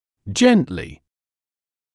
[‘ʤentlɪ][‘джэнтли]мягко, спокойно, осторожно, легко без усилий